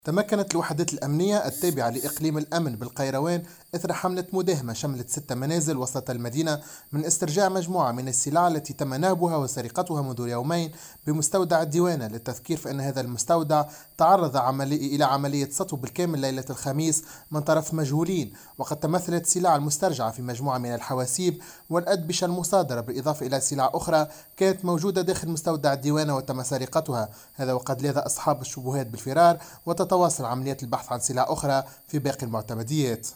مراسلنا في القيروان